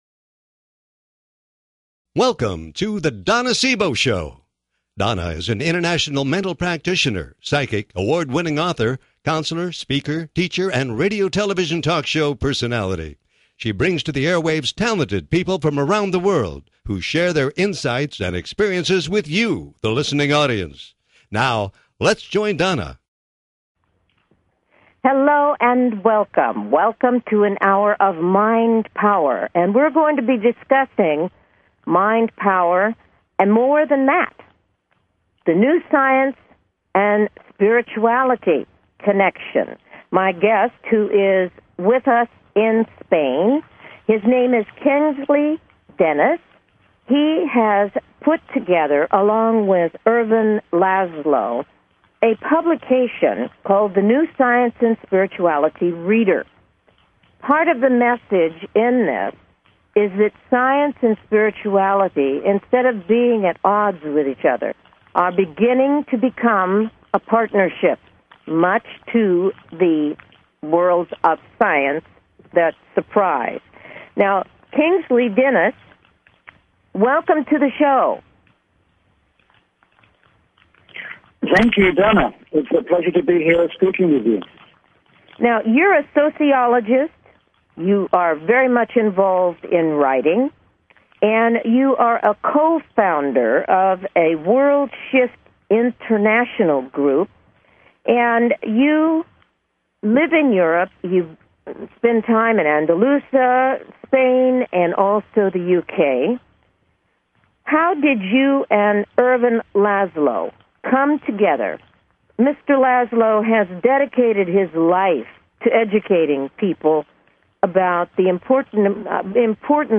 Talk Show Episode
Her interviews embody a golden voice that shines with passion, purpose, sincerity and humor.